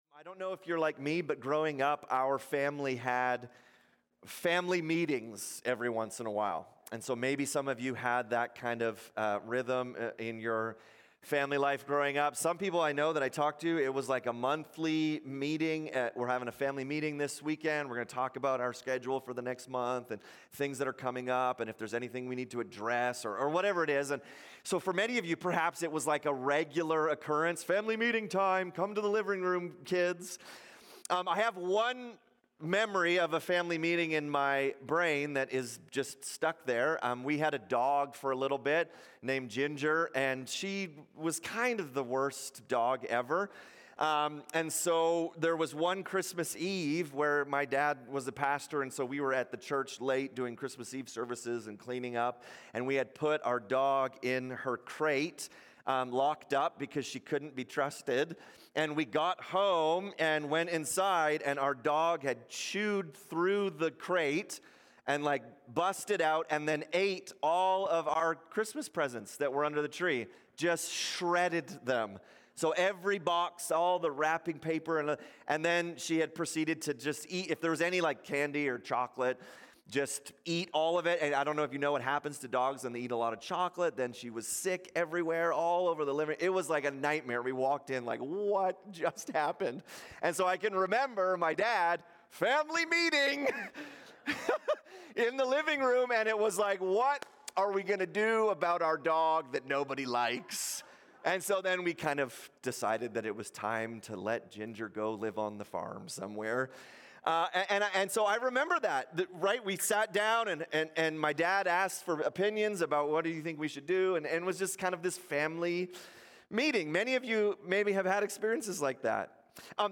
Sunday sermons from North Peace MB Church in Fort St. John, BC